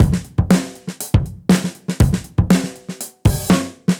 Index of /musicradar/dusty-funk-samples/Beats/120bpm
DF_BeatC_120-01.wav